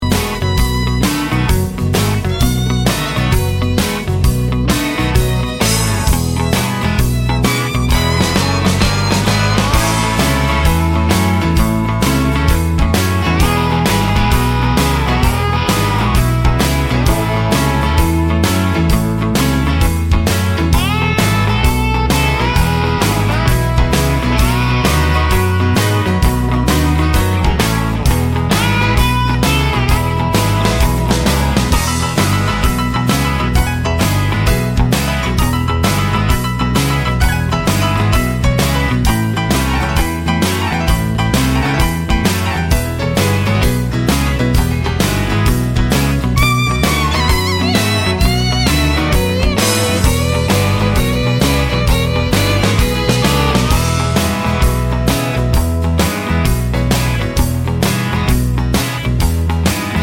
no Backing Vocals Country (Male) 3:14 Buy £1.50